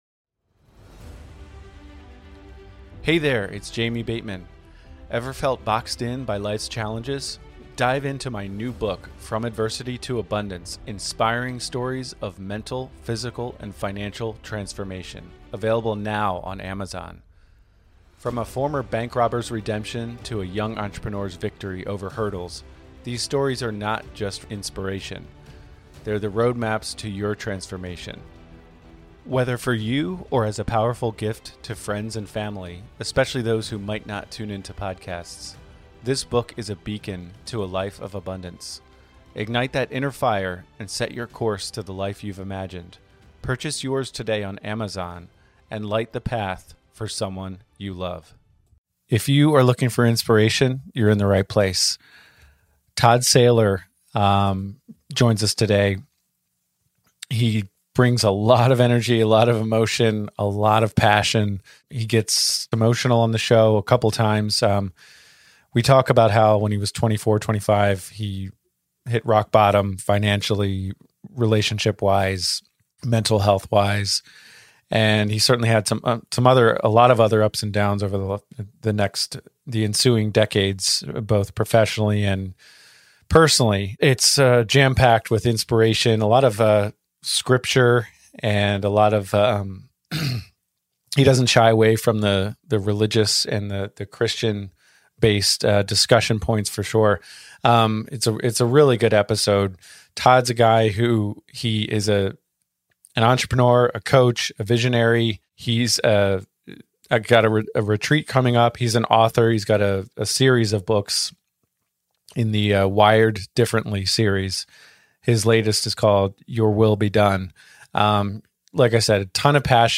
Each week, join us as we dive into the compelling world of real estate through the lens of mental fitness, where challenges transform into opportunities. Our show brings you riveting, interview-based stories from seasoned professionals and inspiring newcomers alike, each sharing their unique journey from struggle to success in